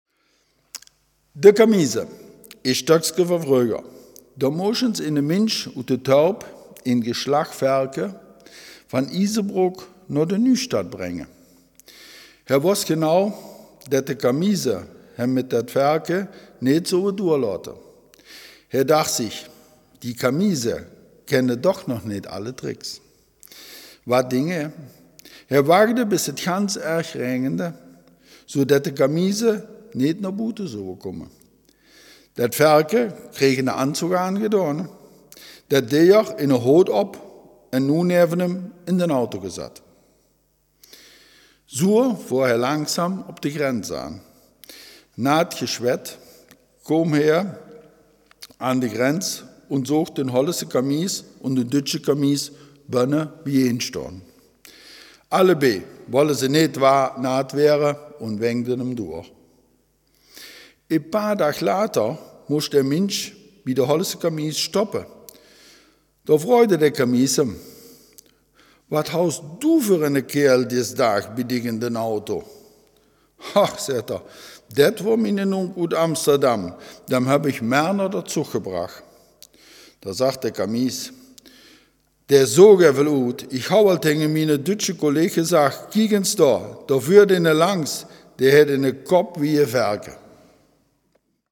Selfkant-Platt
Geschichte